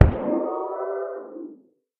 守卫者在水中死亡
Minecraft_guardian_guardian_death.mp3